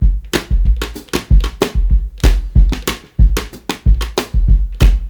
Dusty Shuffle Break.wav